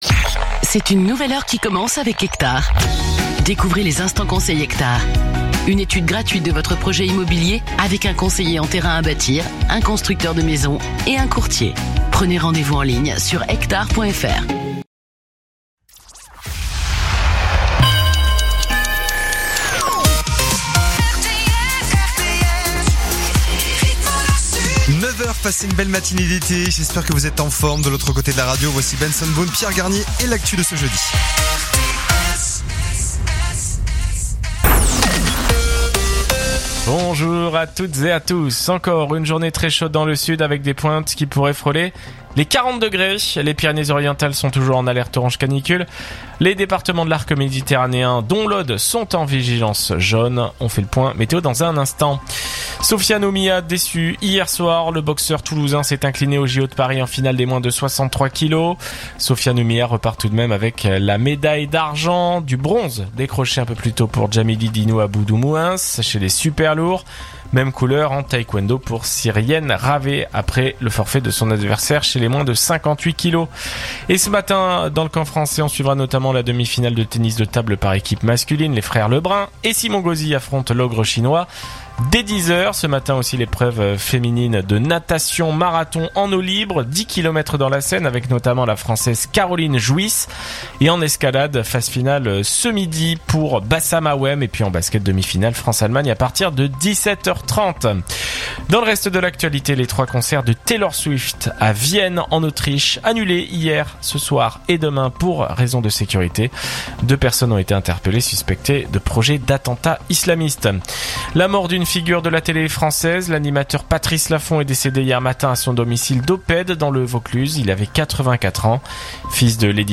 info_narbonne_toulouse_104.mp3